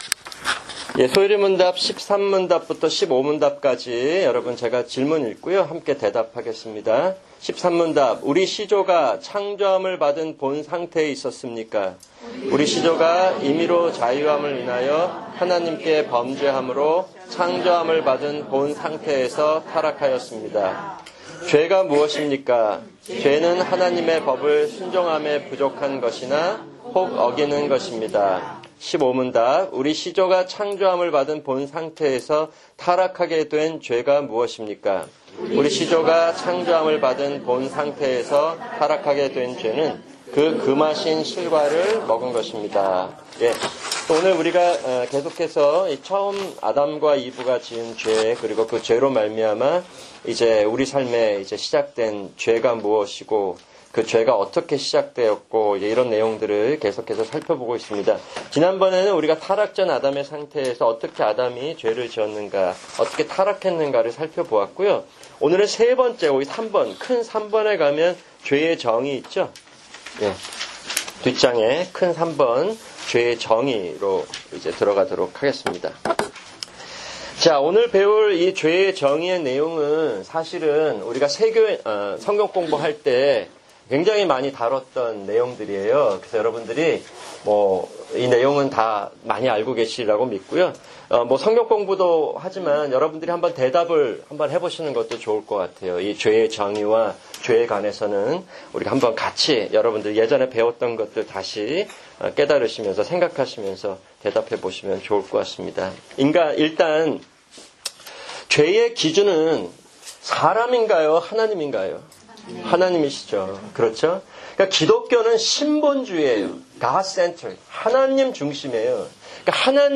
[주일 설교] 시편 67편